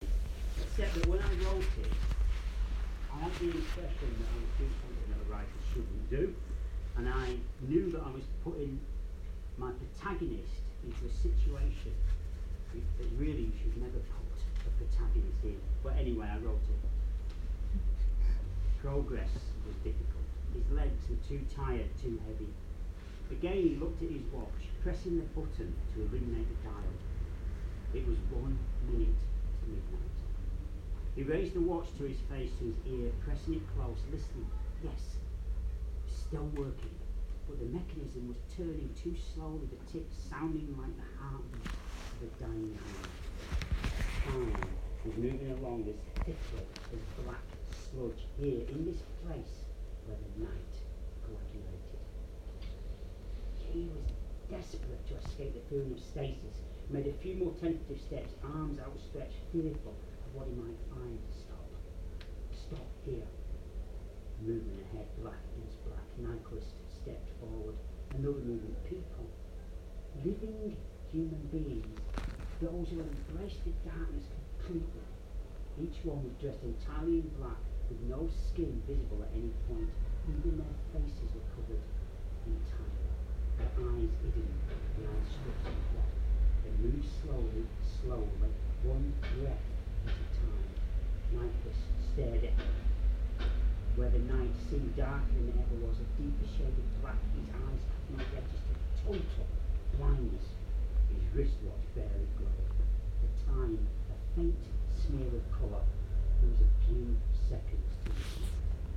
Body Library launch, Brighton